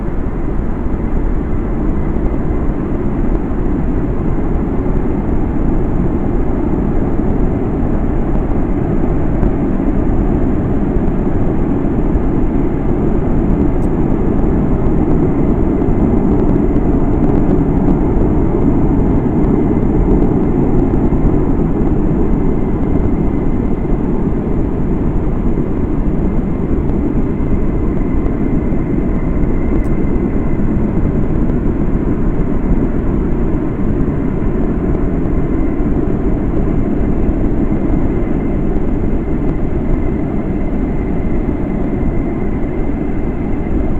2019 WILTON ENVIRONMENTAL NOISE